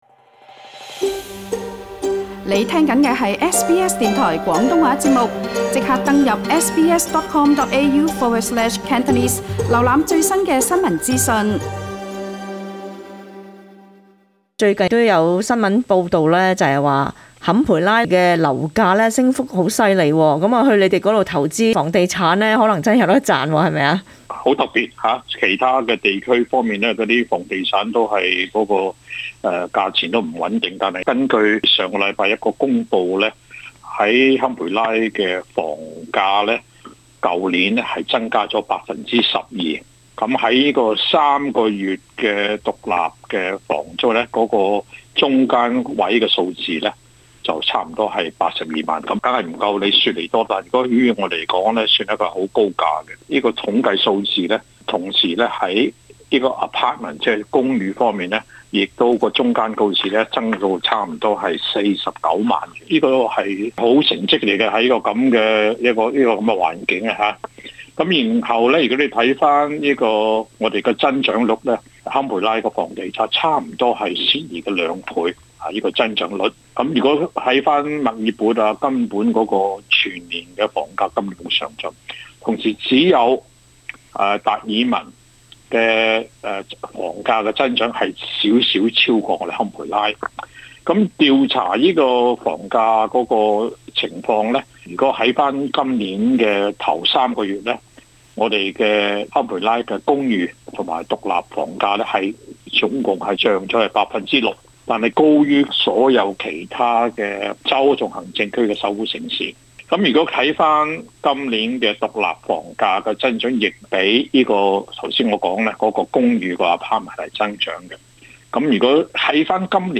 【坎培拉通訊】